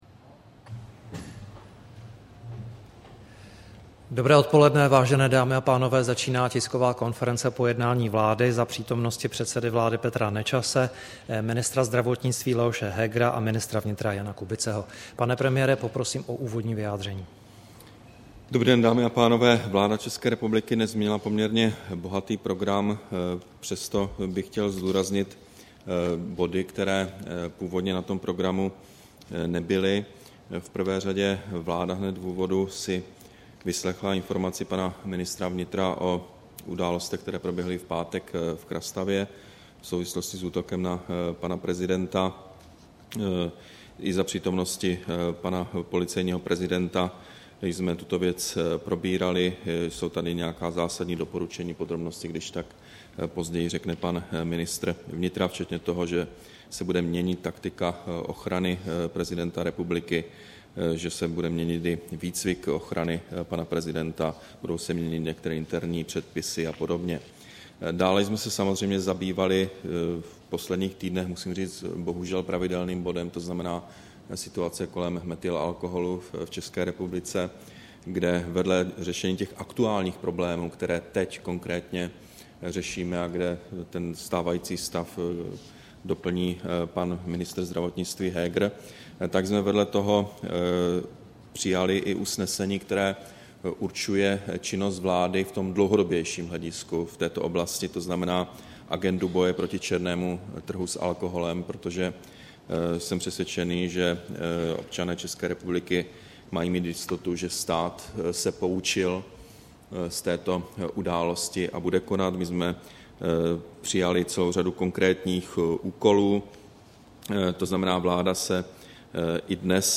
Tisková konference po jednání vlády, 3. října 2012
Začíná tisková konference po jednání vlády za přítomnosti předsedy vlády Petra Nečase, ministra zdravotnictví Leoše Hegera a ministra vnitra Jana Kubiceho.